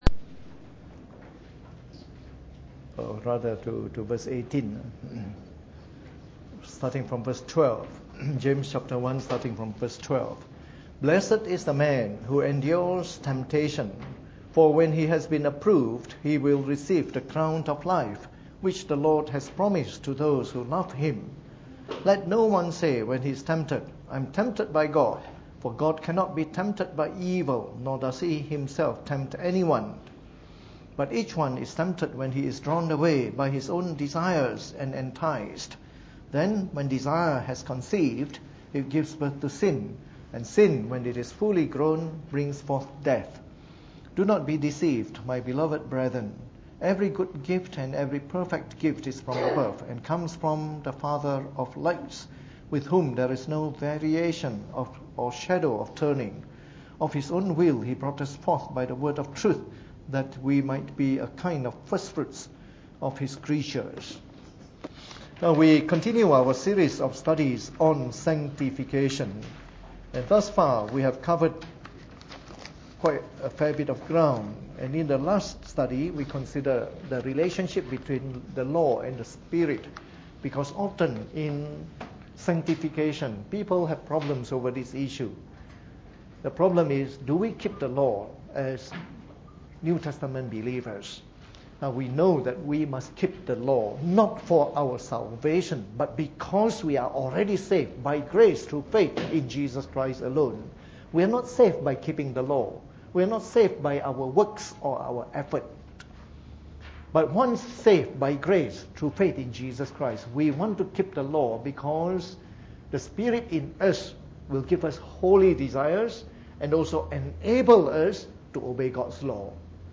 Preached on the 25th of February 2015 during the Bible Study, from our series of talks on Sanctification.